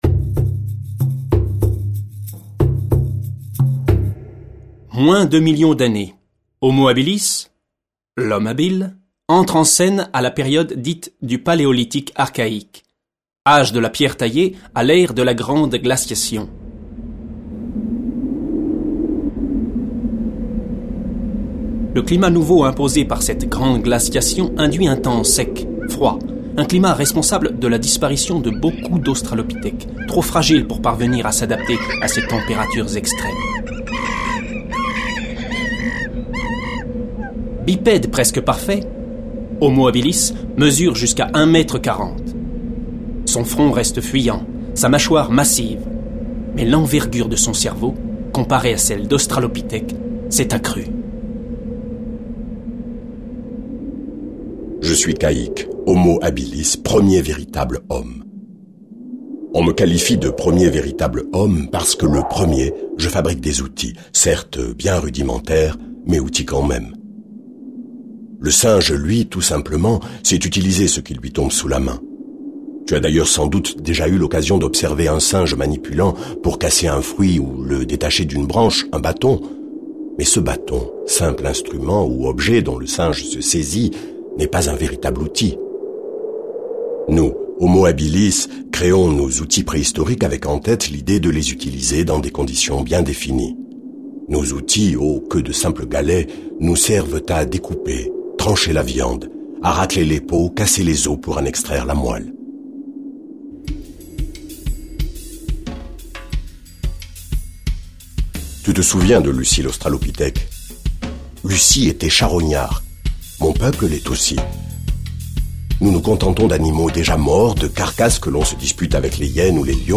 Véritable pièce de théâtre, la Préhistoire relate l'extraordinaire aventure de l'Homme.
Ambiance sonore